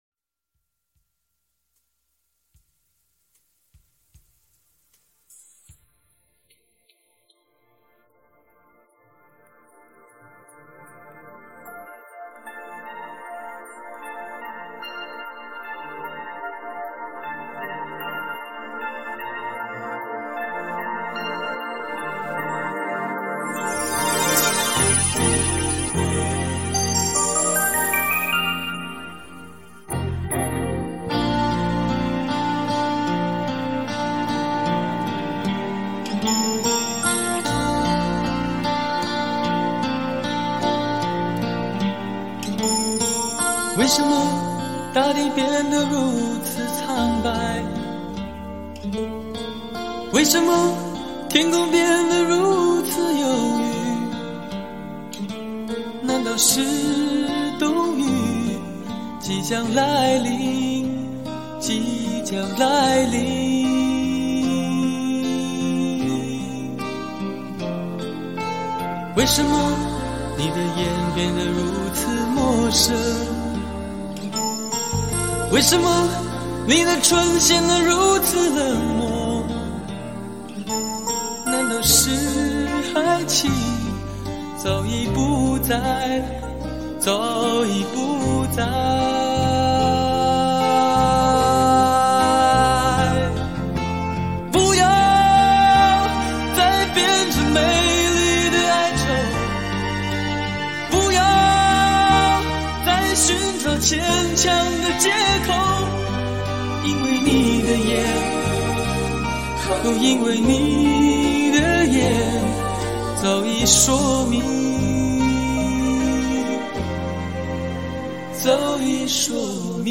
华语